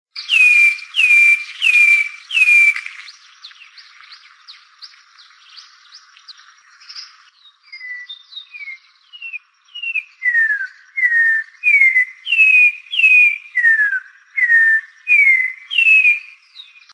Matico (Icterus croconotus)
Nombre en inglés: Orange-backed Troupial
Fase de la vida: Adulto
Localidad o área protegida: Reserva Ecológica Costanera Sur (RECS)
Condición: Silvestre
Certeza: Vocalización Grabada
RECS-Matico-voz-(2).mp3